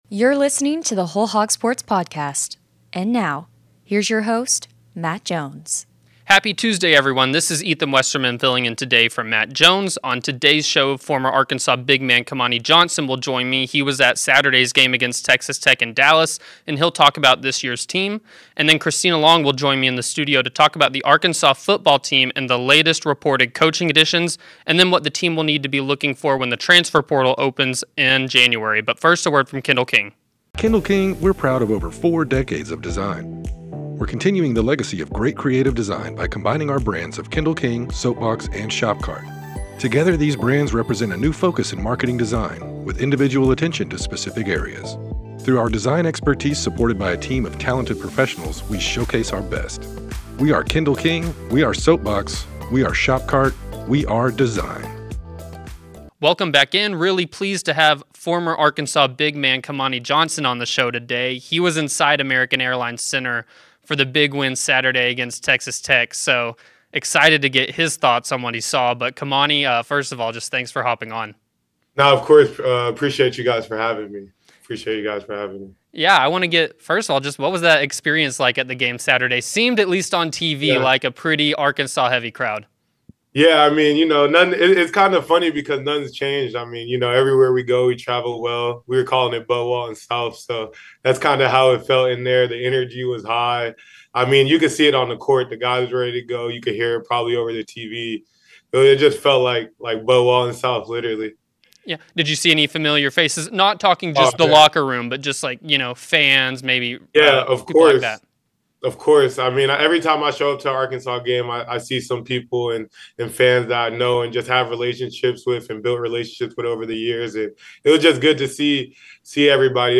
But this week, our guest interview digs into the global cybersecurity hiring trends.